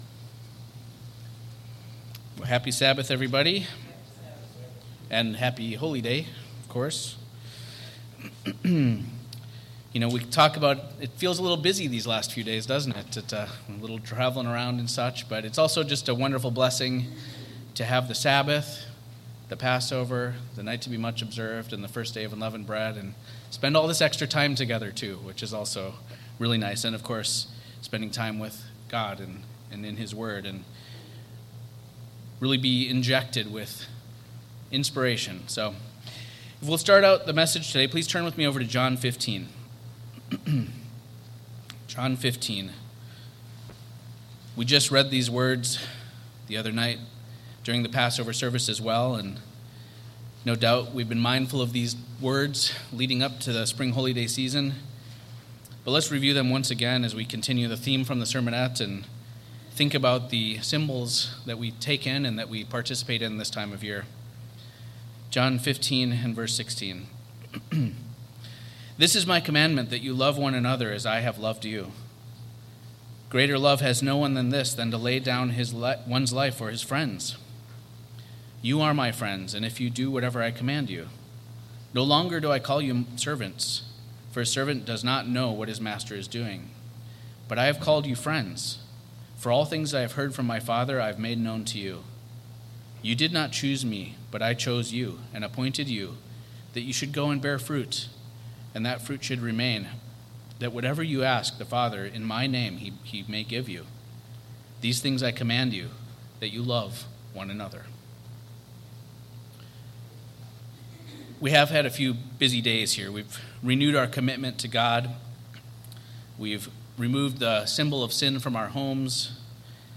The sermon explores the rich symbolism of unleavened bread, representing purity and sinlessness, which aligns with Christ’s teachings on living a life free from sin. The message draws on biblical narratives like the Exodus and the crossing of the Red Sea, while emphasizes the urgency and necessity of leaving behind old ways (symbolized by leaven) and embracing a renewed life committed to God’s commandments.